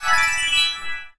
heal_loop_01.ogg